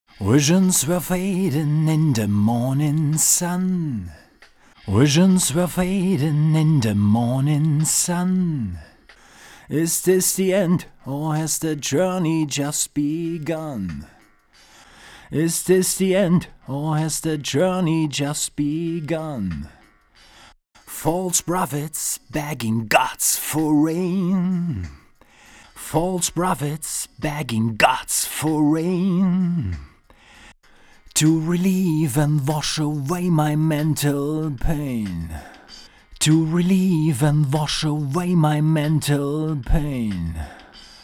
Immer erst ne Phrase unbearbeitet, danach die Phrase bearbeitet. Vorsicht: Männerstimme... Mic und Preamp waren die Teile vom Slate VMS.
Das rohe Signal klingt recht fizzelig.
Die bearbeitete Spur hat auf jeden Fall mehr Gewicht.